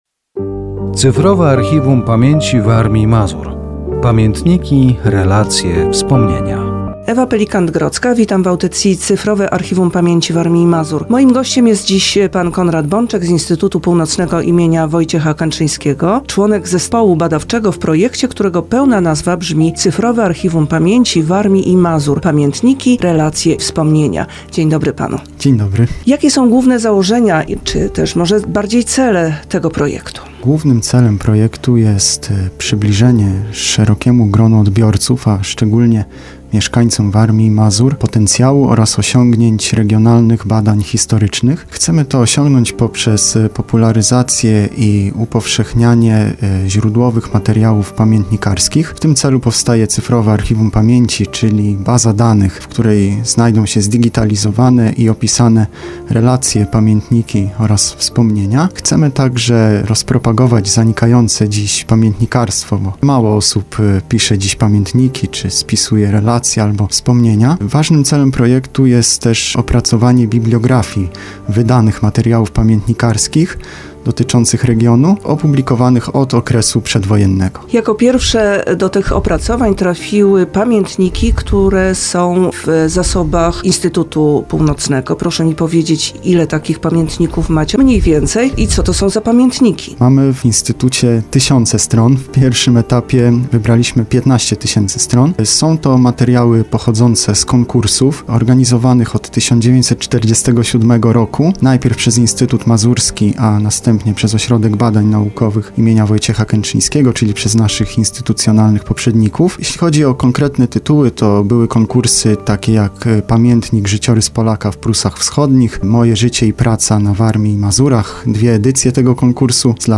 Audycja radiowa poświęcona projektowi "Cyfrowe Archiwum Pamięci Warmii i Mazur. Pamiętniki, relacje, wspomnienia".